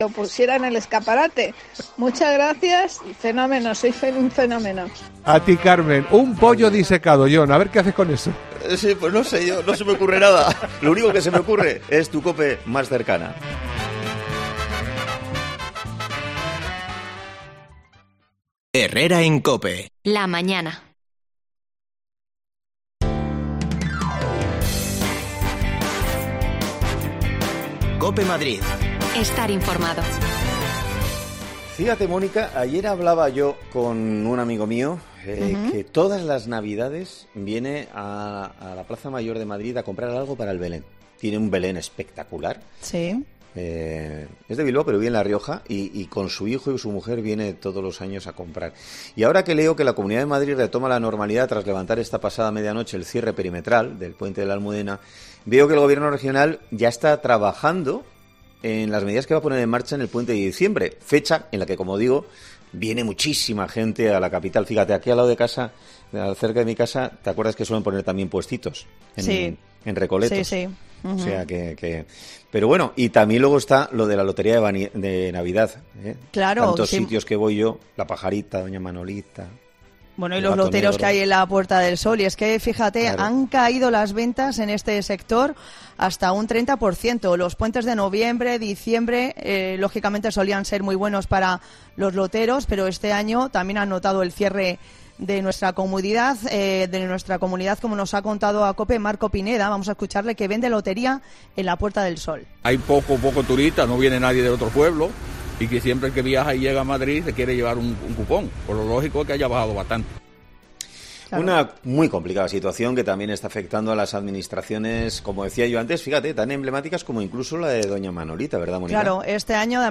AUDIO: Las ventas de loteria en Madrid han caído un 30% con respecto al año pasado. Hablamos con los vendedores del centro para conocer su situación